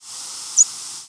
Short rising seeps
American Redstart ex1 ex2 ex3